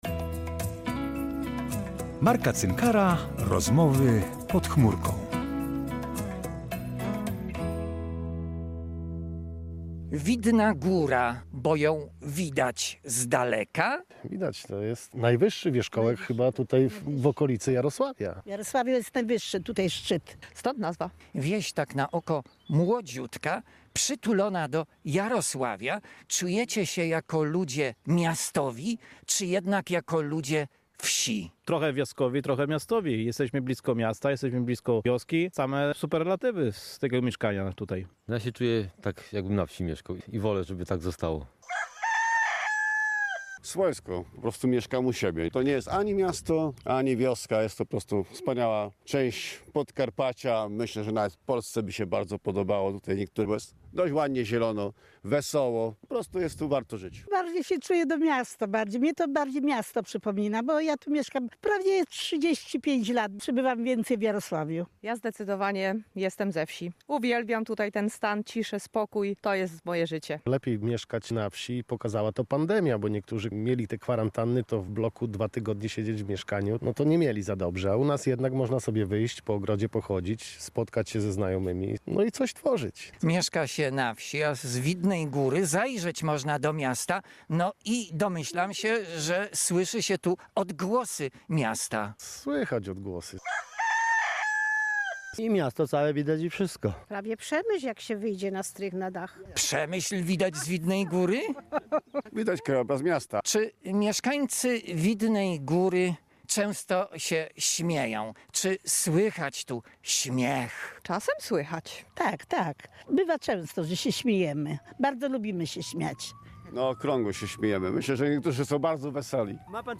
Audycje
wybrał się na Widną Górę k. Jarosławia, by z mieszkańcami tej wsi porozmawiać o pozytywnym wpływie śmiechu na cały organizm człowieka: poprawia on odporność, rozładowuje stres, wywołuje wydzielanie się endorfiny, która zmniejsza między innymi ból fizyczny i cierpienie psychiczne.